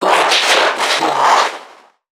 NPC_Creatures_Vocalisations_Infected [41].wav